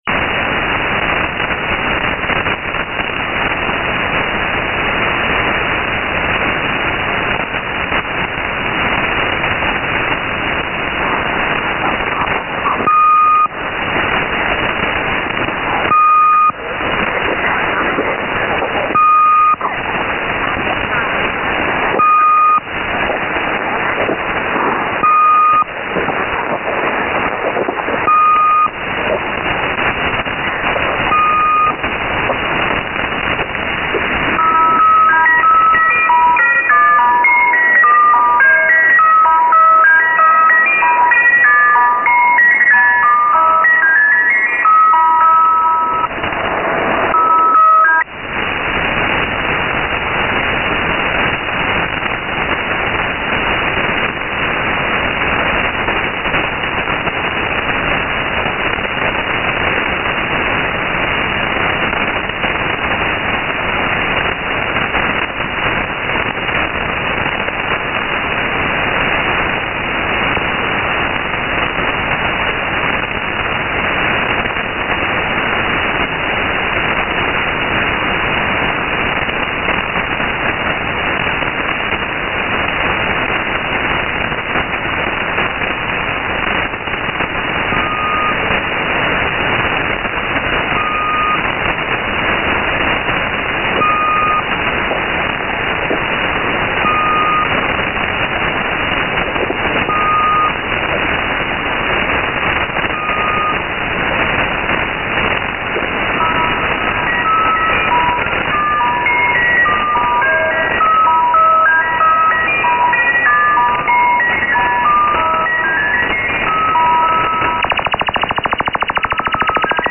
Someone playing an old recording of this former Spy Numbers station.
Here is my SDR recording of the transmission:
Also some Lincolnshire Poacher audio at the end.